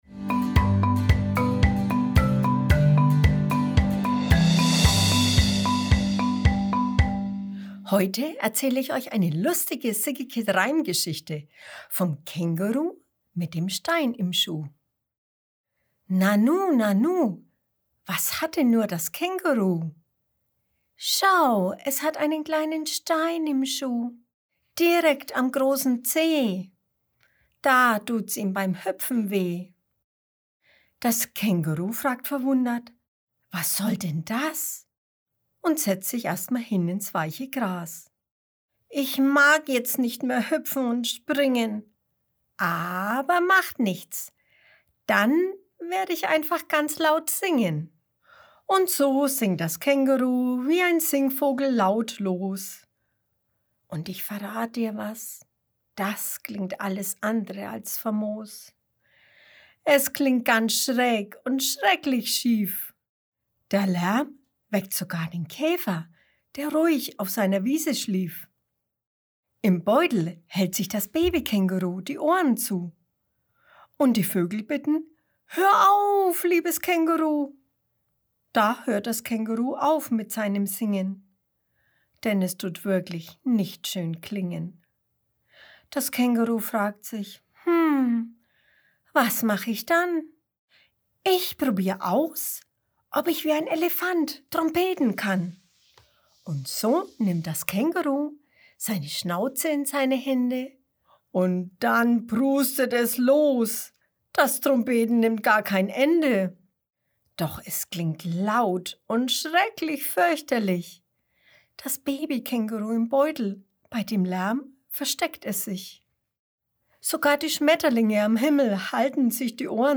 April 2021 Kinderblog Reime, Vorlesegeschichten Nanu, nanu, was hat denn nur das Känguru?